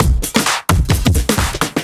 OTG_TripSwingMixA_130b.wav